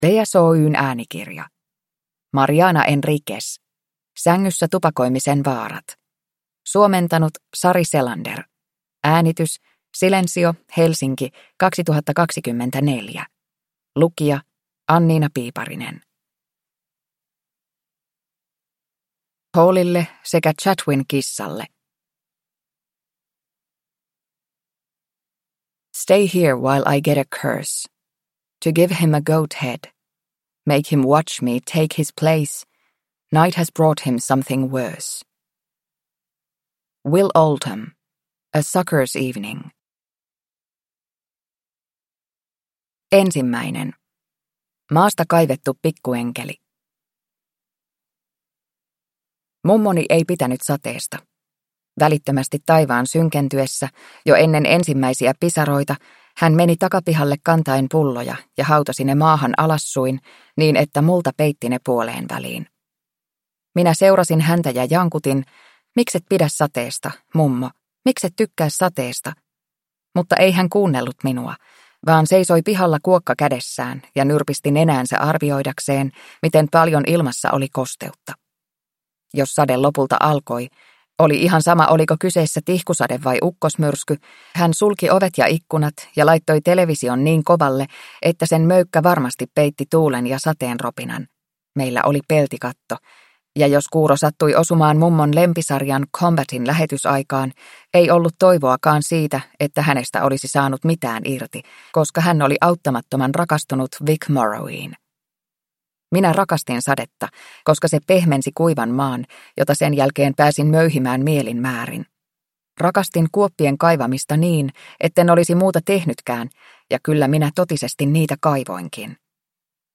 Sängyssä tupakoimisen vaarat – Ljudbok